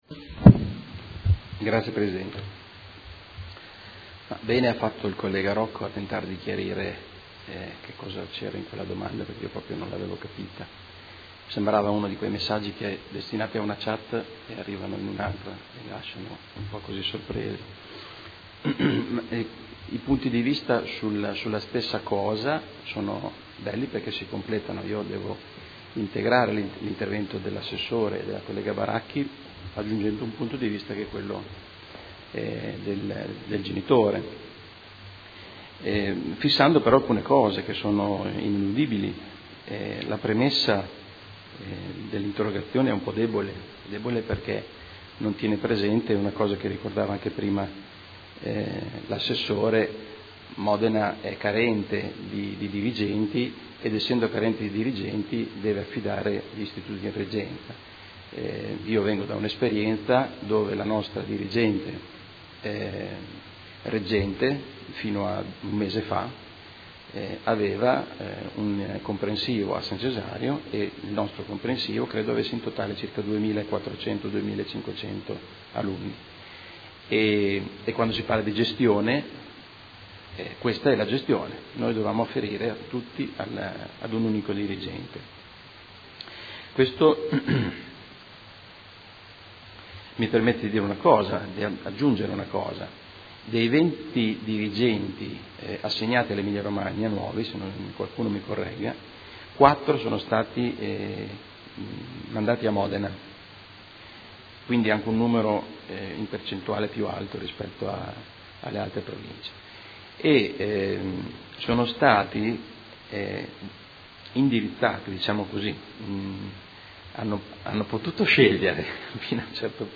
Seduta del 5/11/2015. Interrogazione del Consigliere Rocco (FaS) avente per oggetto: Ridimensionamento rete scolastica. Dibattito